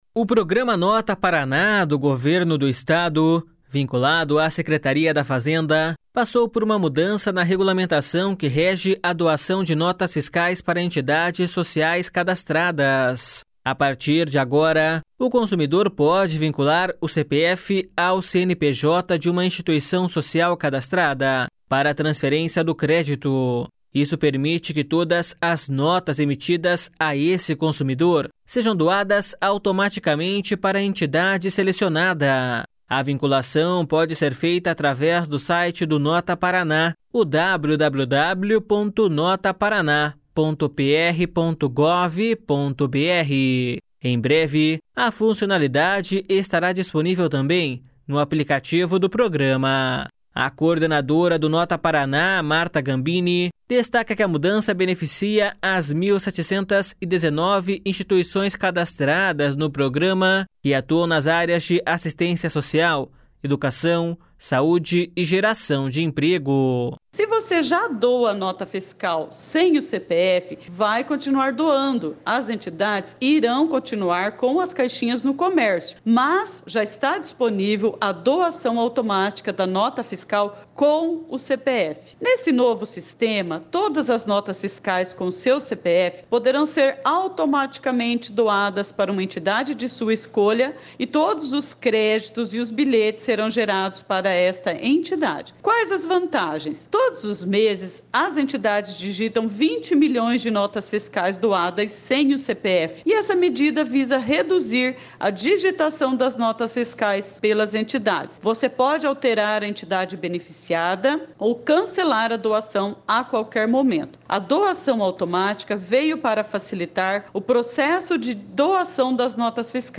Não é possível doar simultaneamente para mais de uma entidade no mesmo mês. (Repórter: